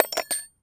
metal_small_movement_07.wav